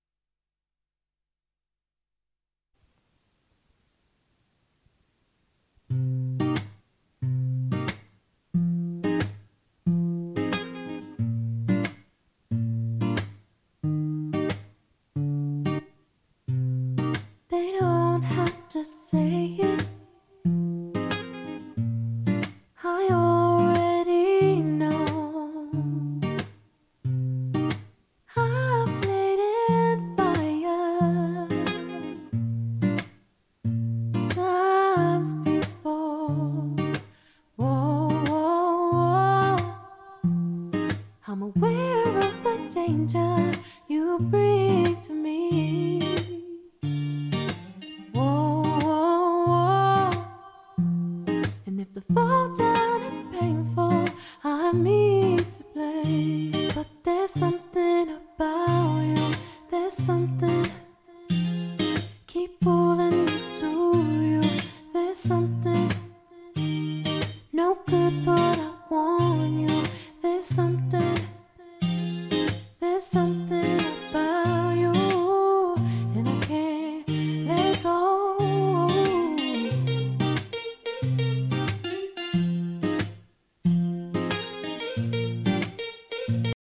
A great sounding cassette deck from AKAI with its popluar GX (glass) heads.
Recording and Playback of the recording with the GXC-704D: